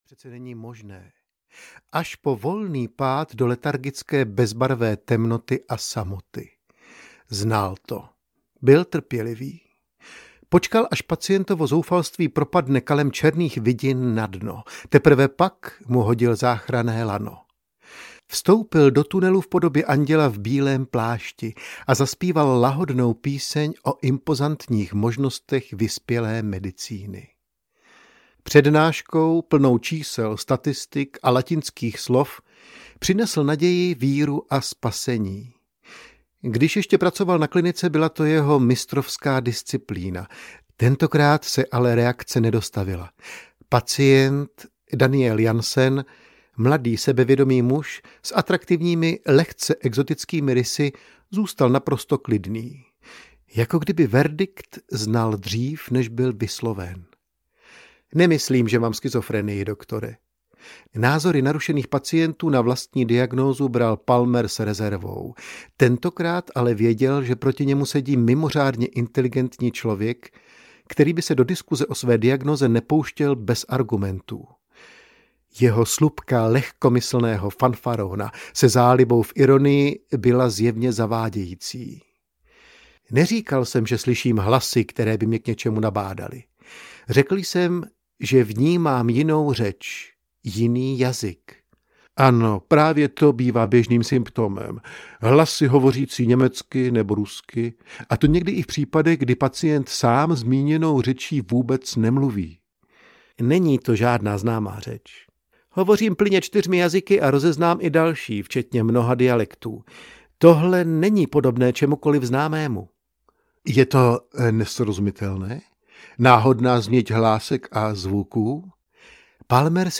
Desperanto audiokniha
Ukázka z knihy